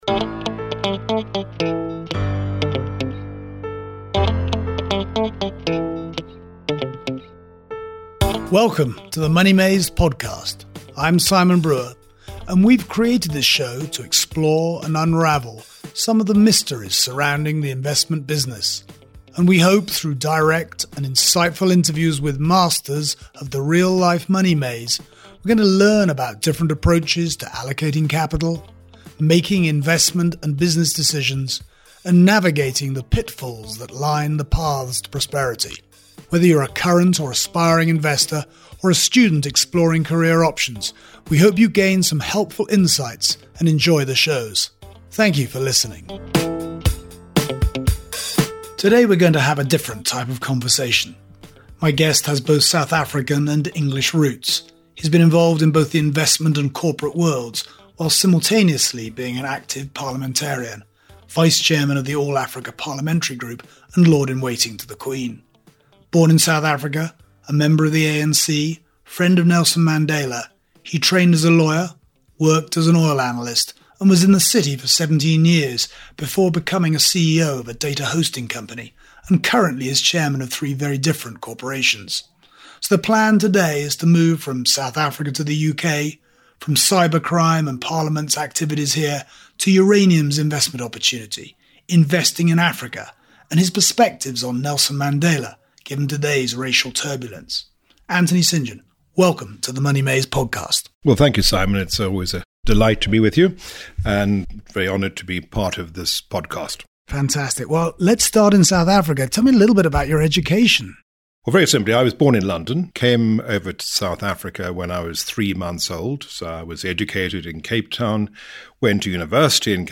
In a slightly different conversation for the MoneyMaze Podcast, Anthony St John shares his fascinating journey and set of insights.